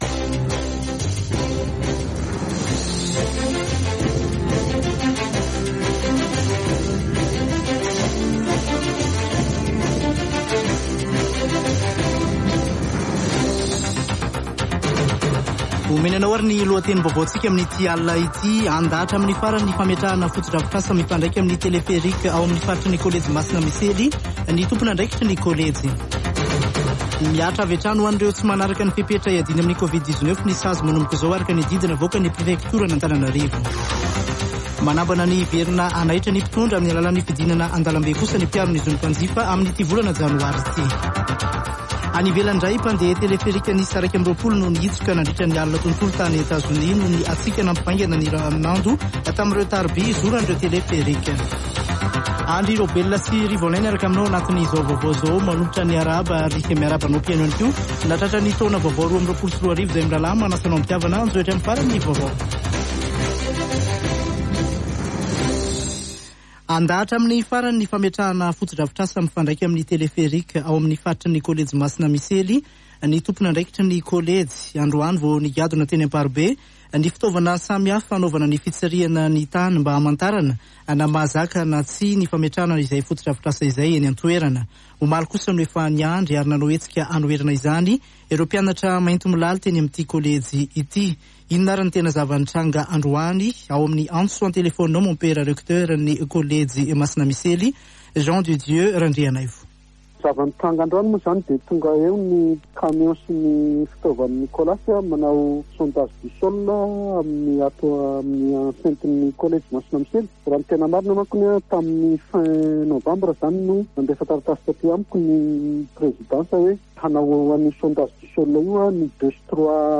[Vaovao hariva] Alatsinainy 3 janoary 2022